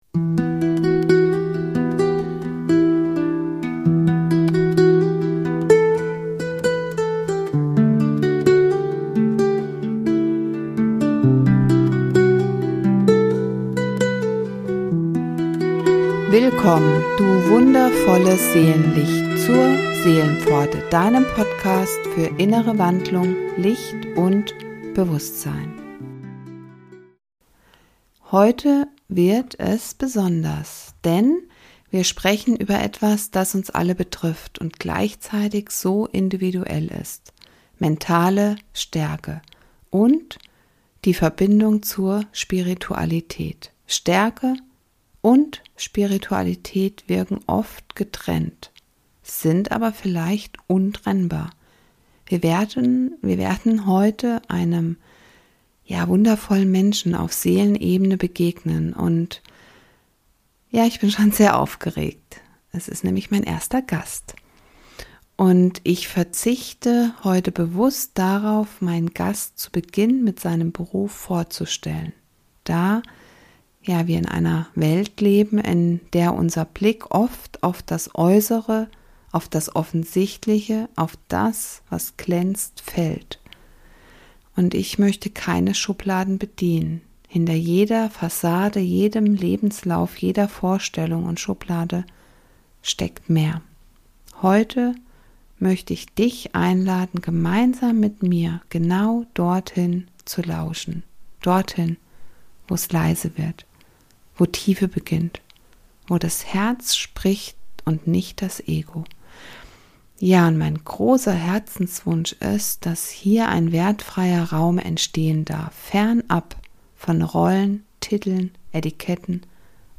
Heute bin ich im Gespräch mit einem wundervollen Menschen.